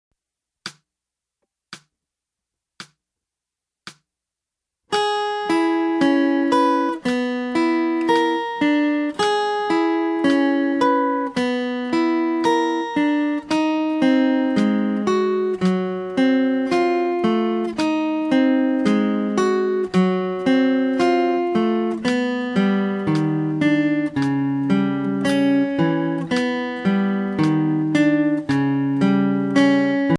Voicing: Guitar w/C